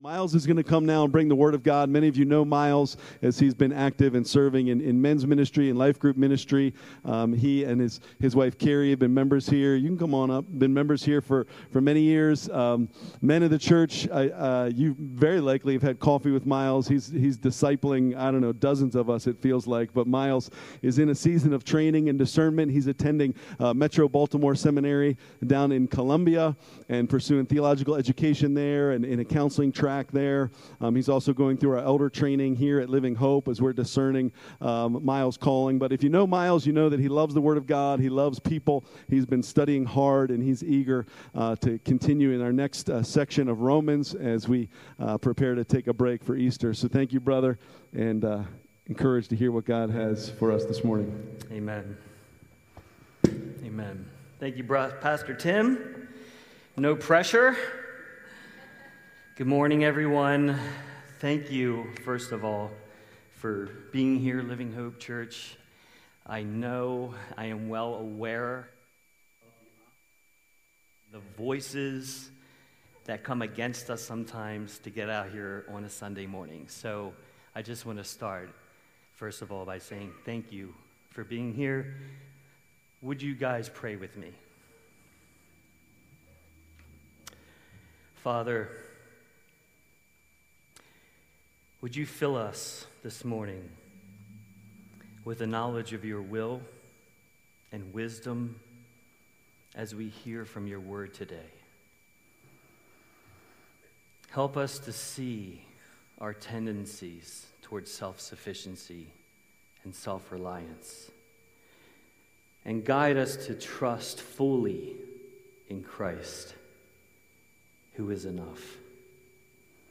March 15, 2026 Worship Service Order of Service: Welcome Call to Worship Praise Songs Community News Children’s Dismissal Worship Song Sermon Closing Song